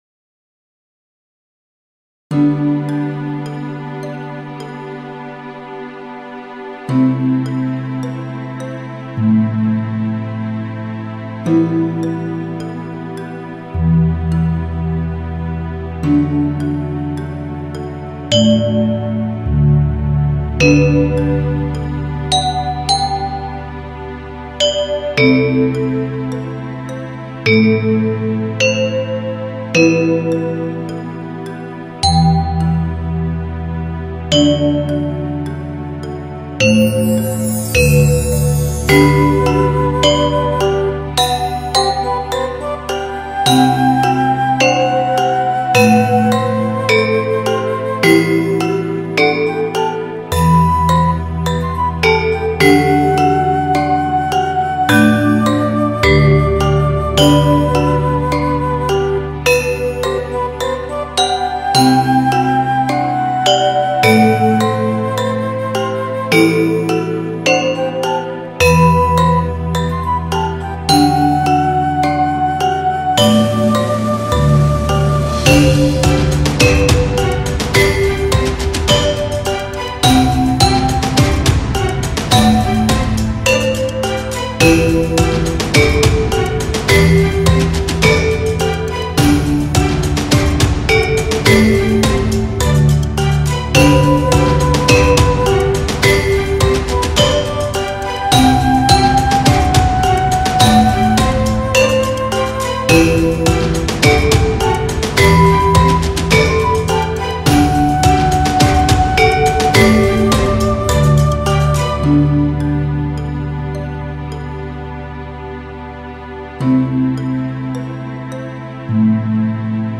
PurityJavaneseGamelan.Cv2M1whp.mp3